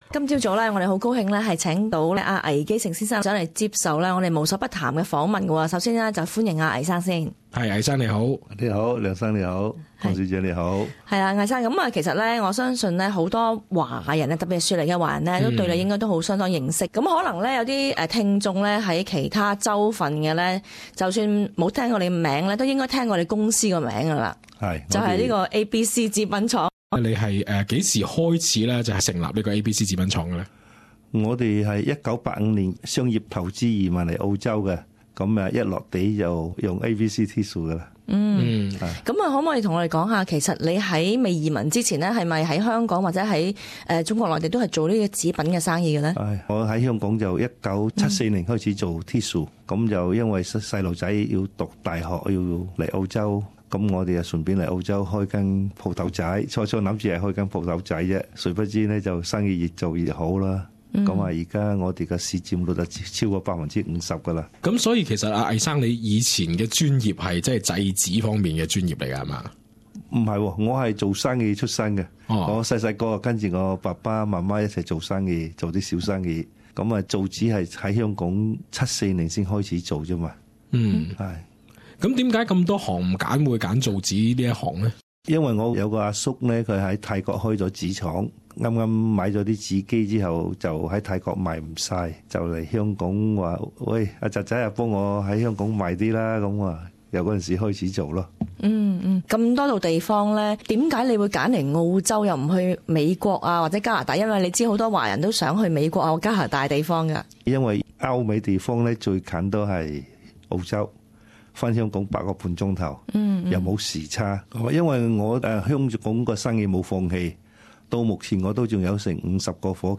作客【无所不谈】节目。